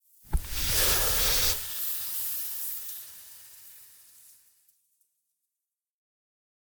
ITEM_cigarette.ogg